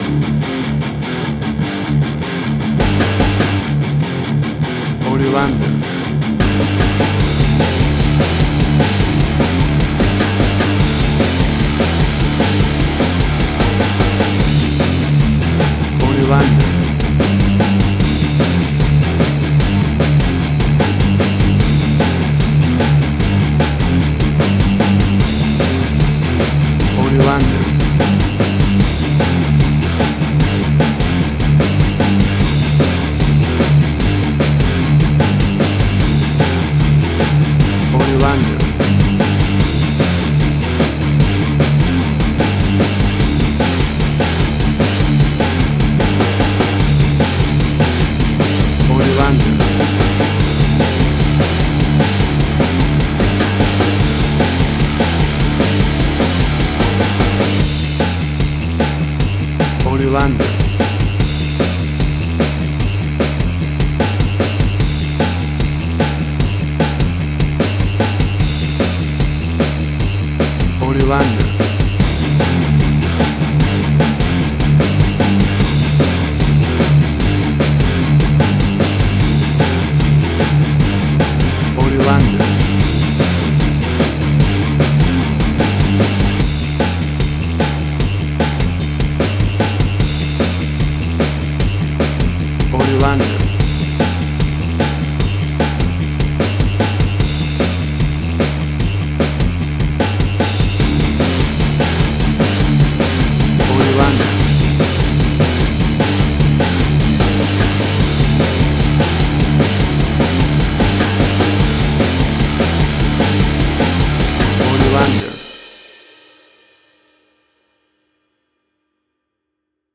Hard Rock
Heavy Metal
Tempo (BPM): 151